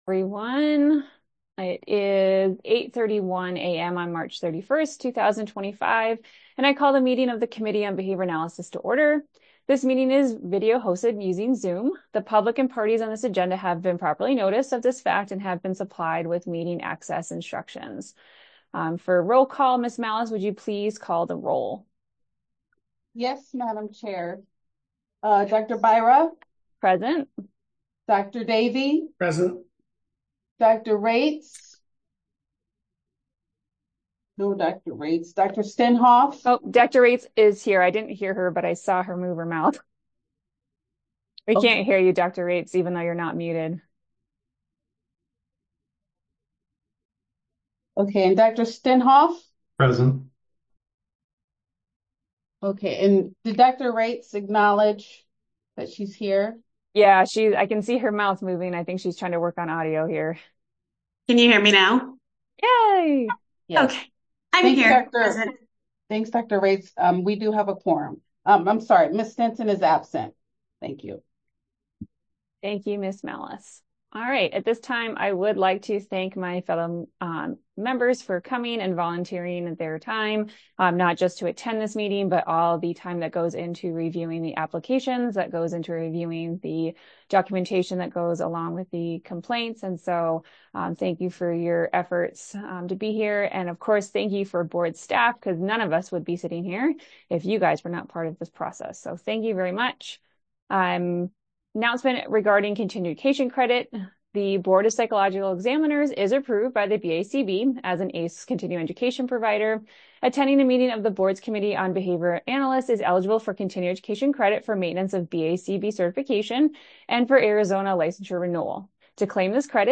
Committee on Behavior Analysts Meeting | Board of Psychologist Examiners
Members will participate via Zoom.